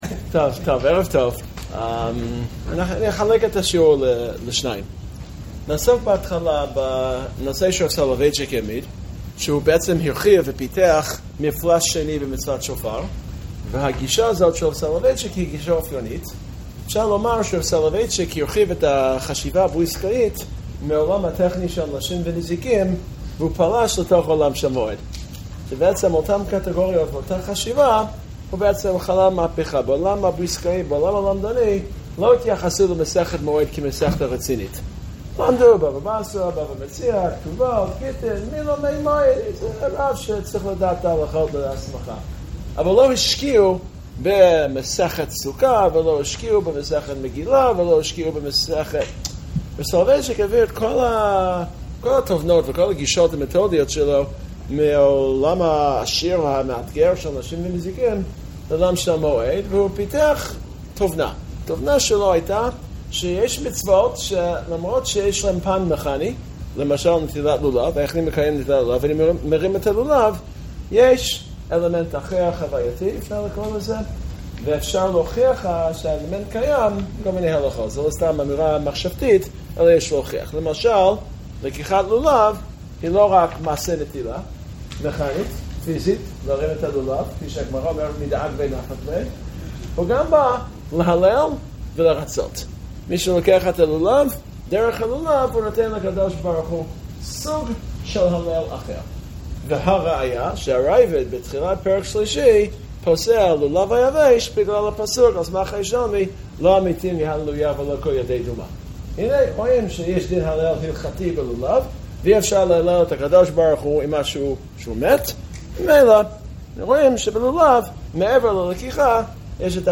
השיעור הועבר במסגרת "קרוב אליך" - שיעורי ישיבת הר עציון בחשמונאים, ביום שלישי י"ט באלול תשפ"ג בבית הכנסת הרימון